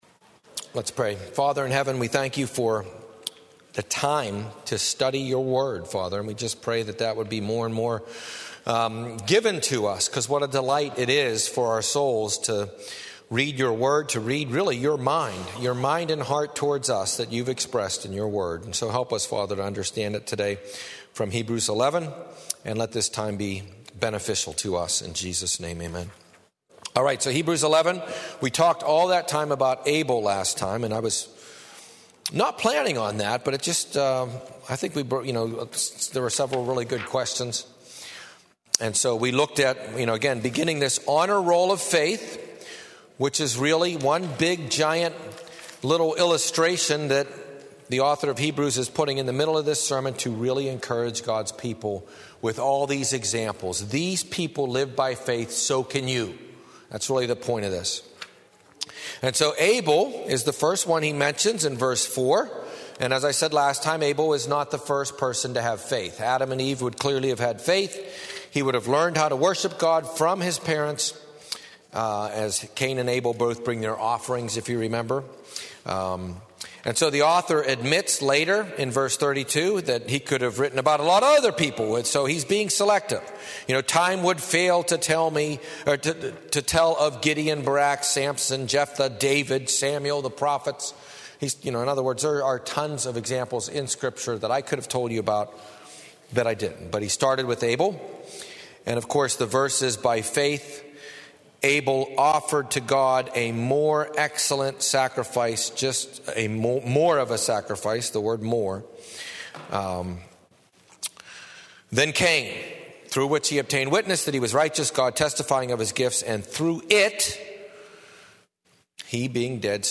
Test Sermon 2